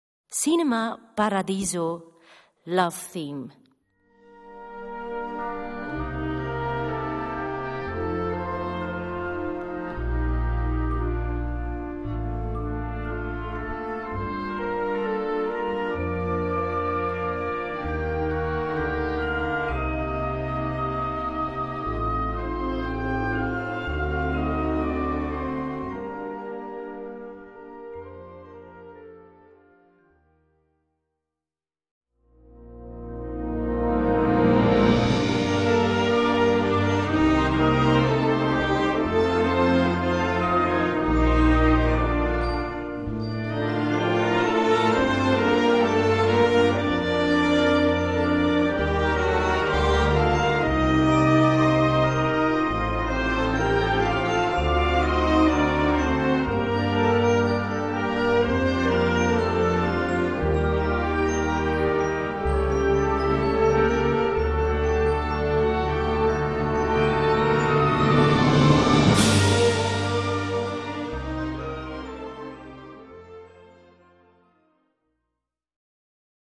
Gattung: Filmmusik
Besetzung: Blasorchester
stilvollen und romantischen Ruhepunkt